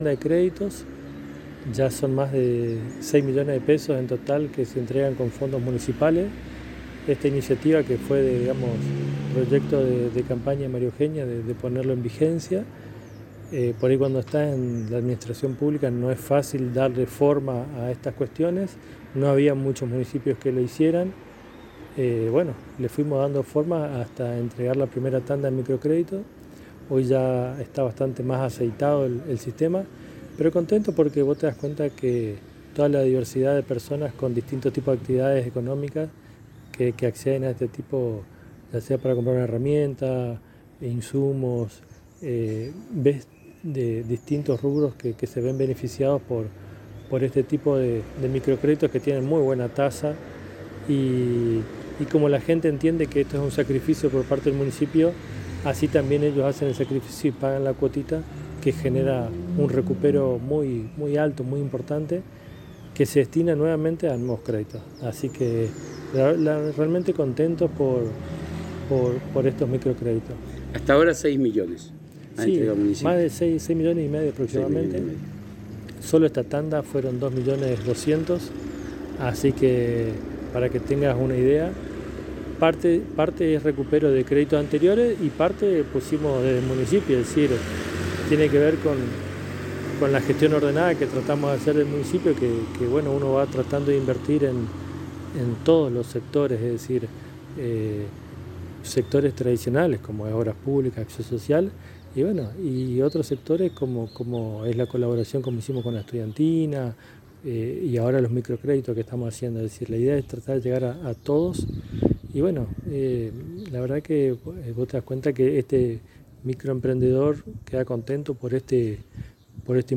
En la oportunidad de la entrega de los créditos, el Secretario de Hacienda Javier Safrán en diálogo exclusivo con la ANG manifestó su alegría al ver que esta idea se materializa y que hay una variedad de personas y proyectos que se han financiado y ayudado a nacer o crecer.